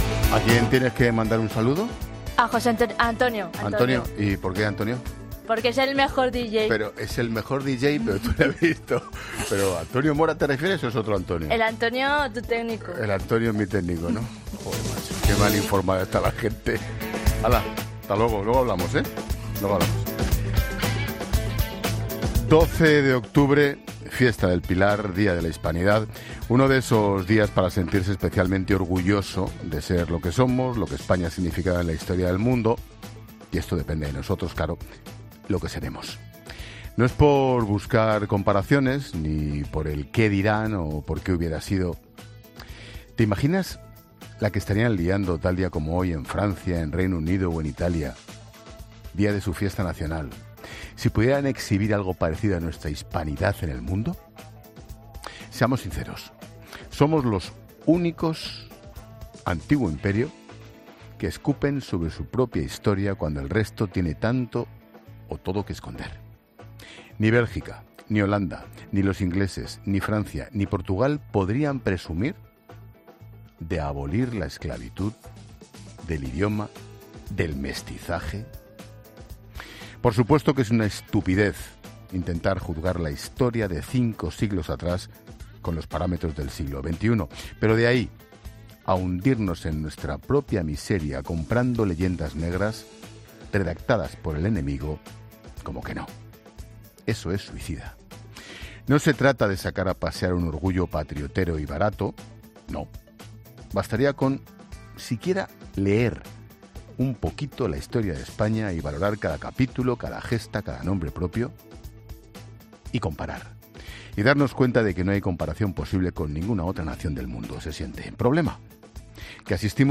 Monólogo de Expósito